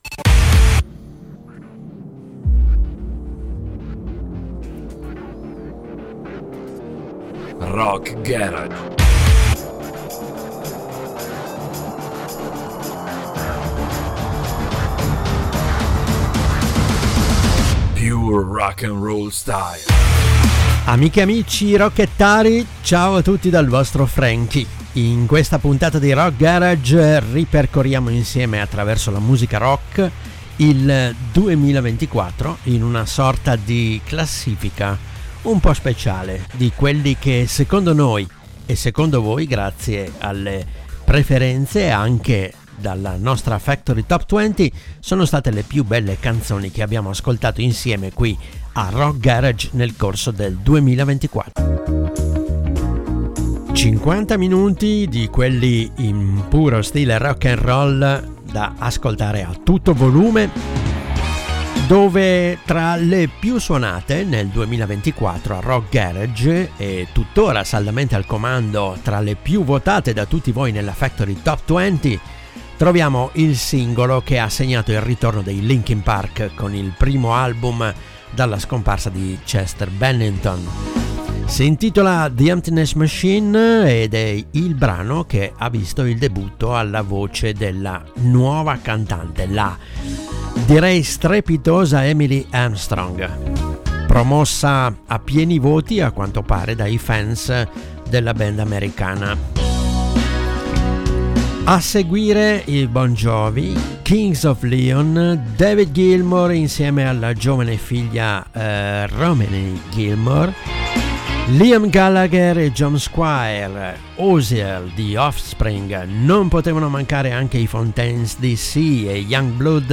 canzoni rock